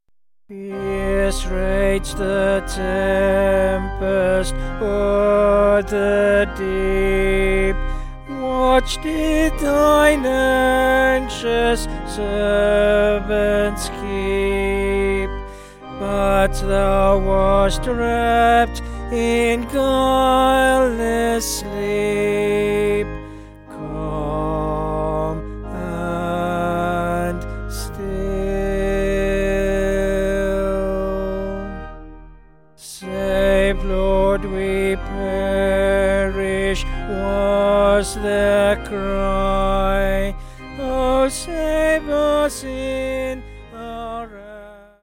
Vocals and Organ